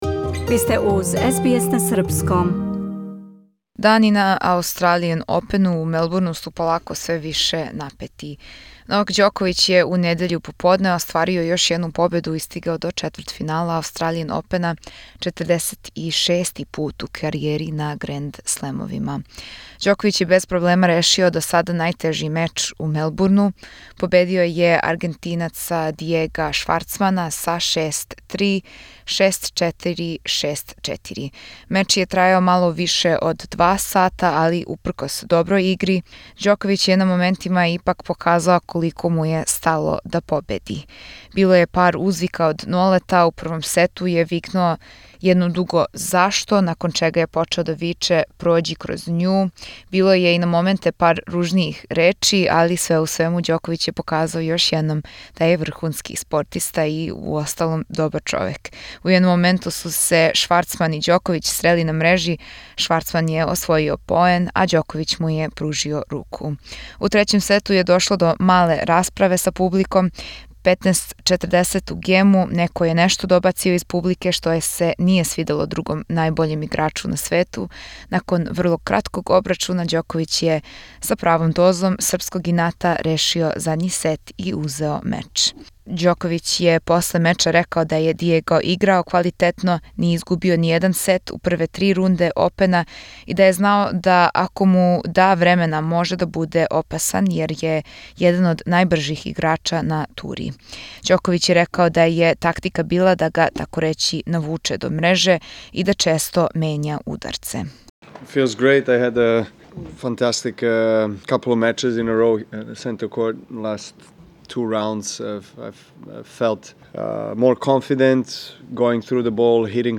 Novak Djoković is continuing another successful run at the Australian Open, beating Diego Schwartzman in straight sets. What does he think of upcoming clash against Canadian Miloš Raonić? SBS Serbian spoke to him after the match.